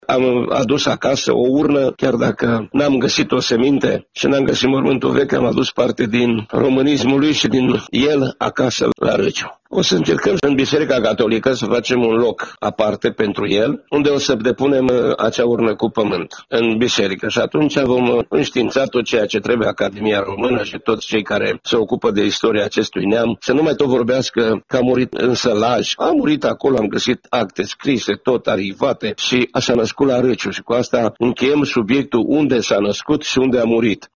Un astfel de monument va fi ridicat anul viitor și în Râciu, a precizat pentru Radio Târgu-Mureș, primarul comunei, Ioan Vasu: